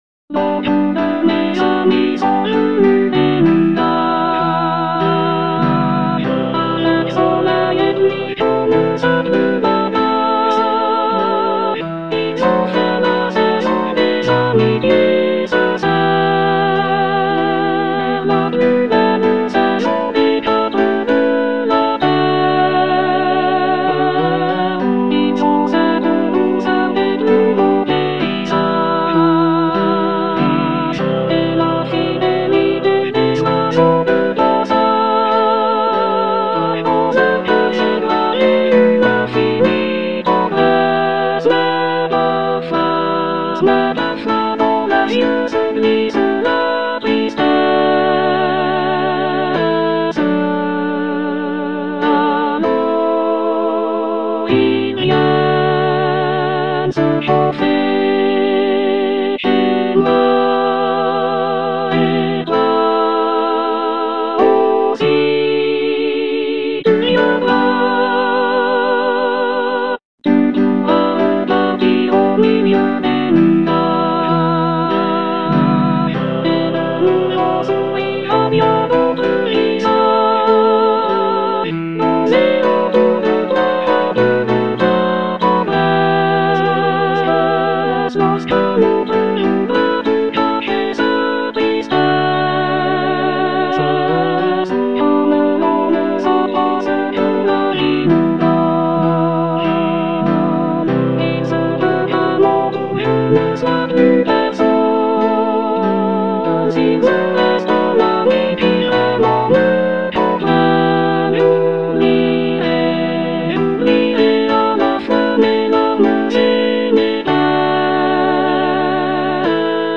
Soprano (Emphasised voice and other voices)
piece for choir